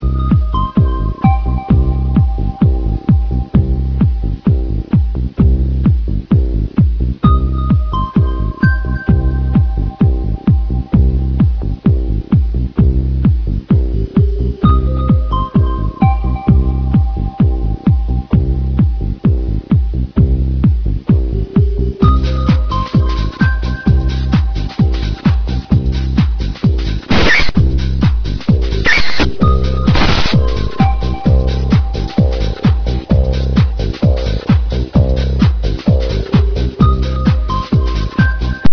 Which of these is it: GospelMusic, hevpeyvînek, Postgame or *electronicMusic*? *electronicMusic*